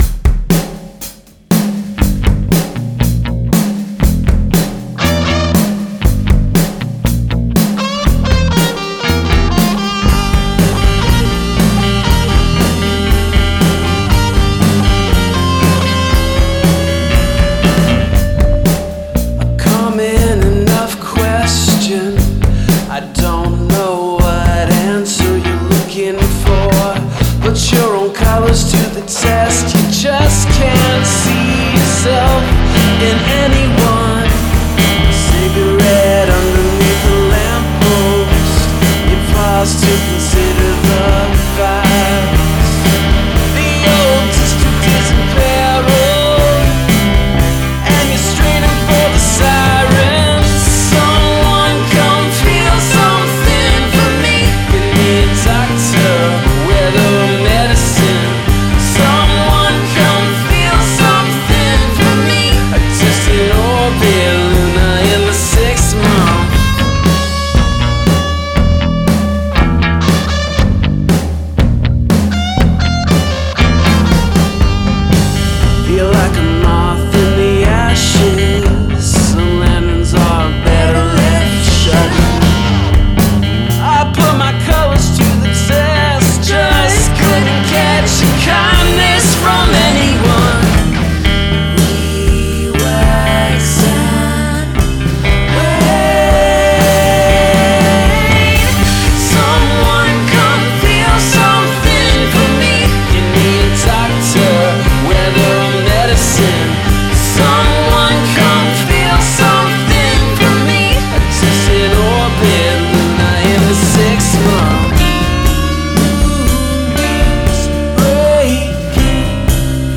Snare sounds a little boxy.
Love the little vocal flourish around 2:08.
the loud/quiet arrangement